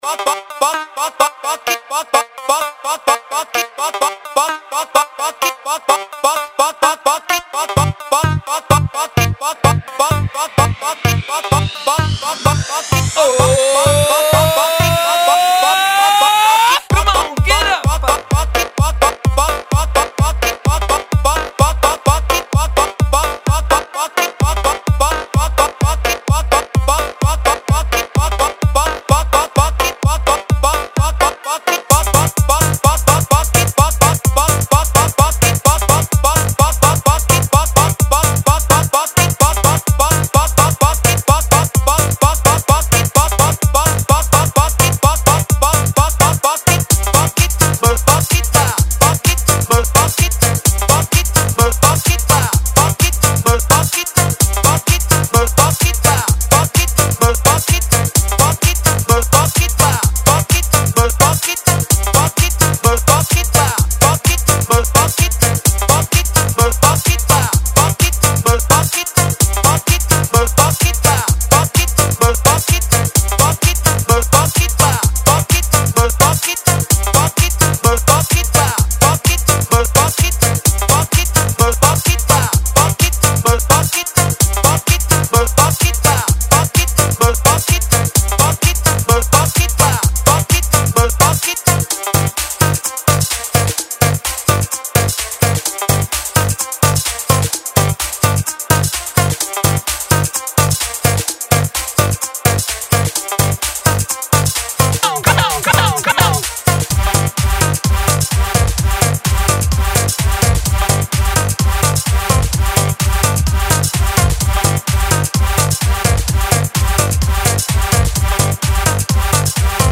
Electro
EDM track